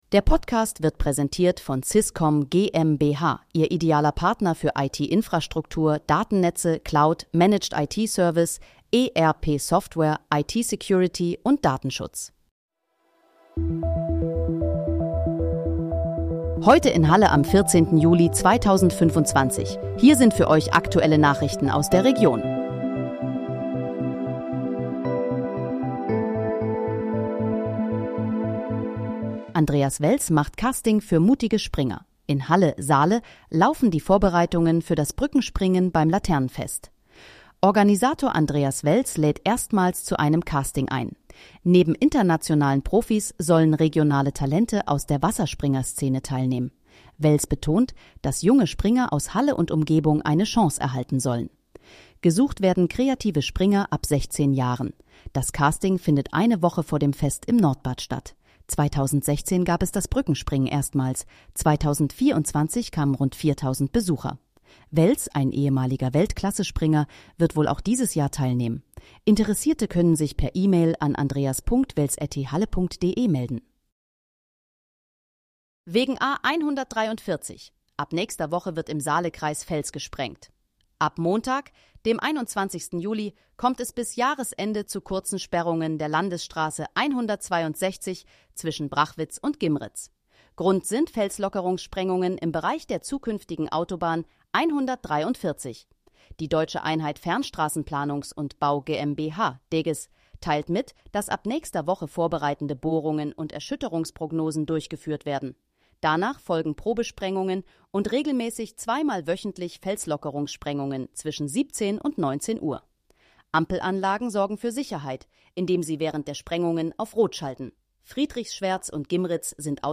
Heute in, Halle: Aktuelle Nachrichten vom 14.07.2025, erstellt mit KI-Unterstützung